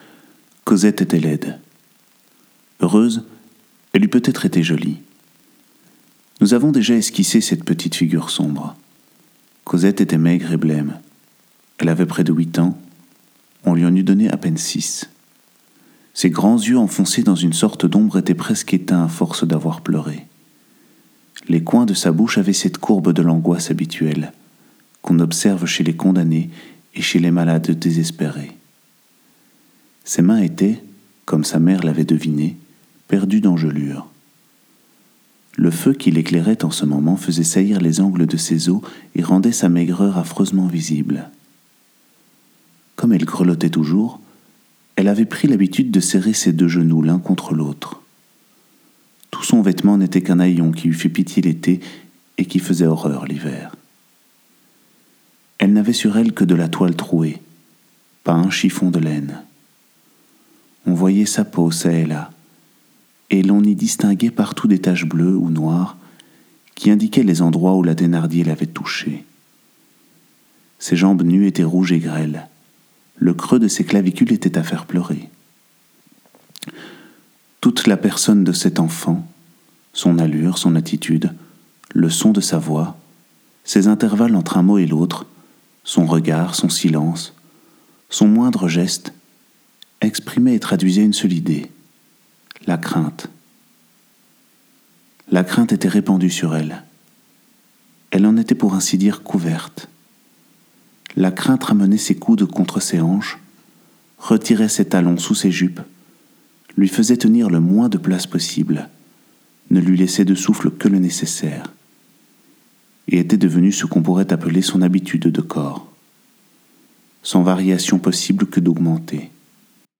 audiobook extrait